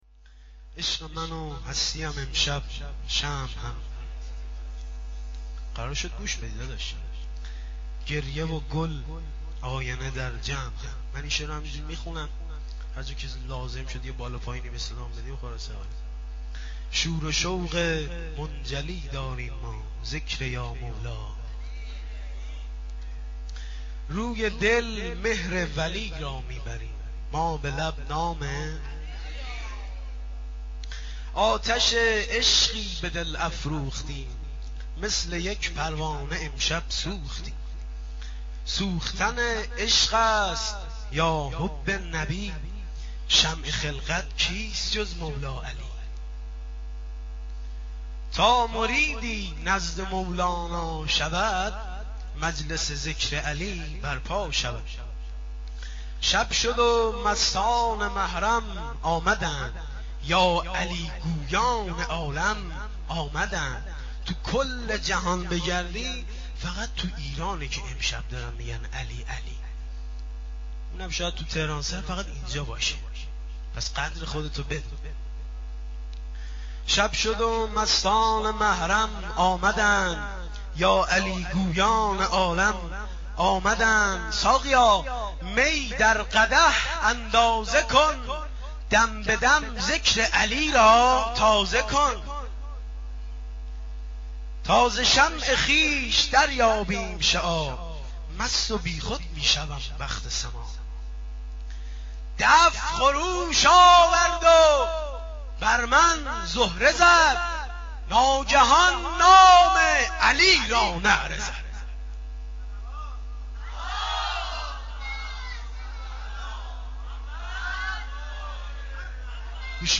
جشن ولادت امام علی (ع)؛ مدح